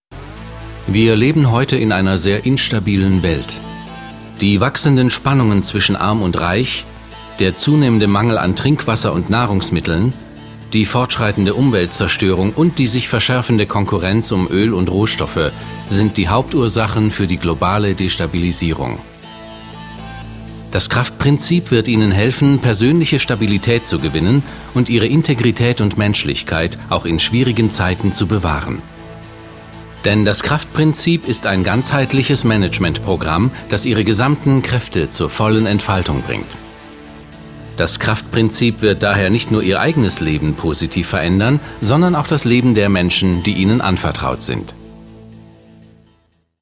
Profi-Sprecher deutsch.
Sprechprobe: eLearning (Muttersprache):
german voice over artist